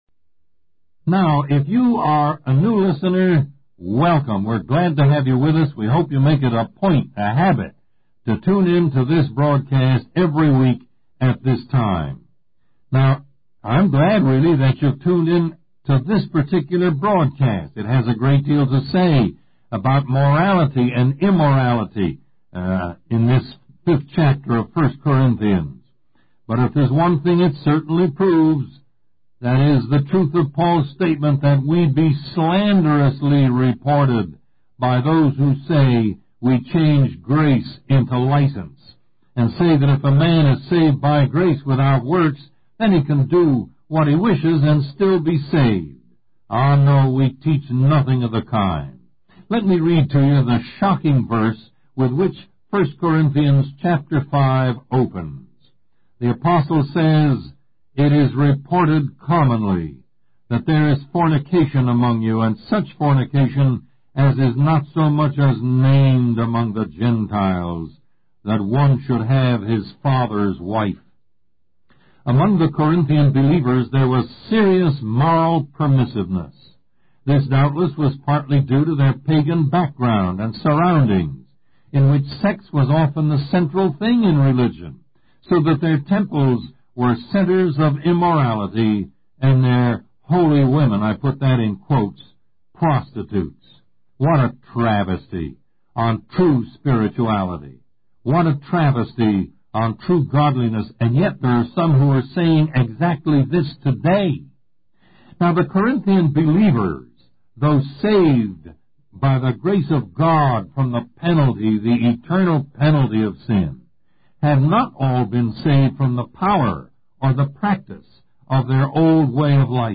Lesson 23: Your Boasting is Not Good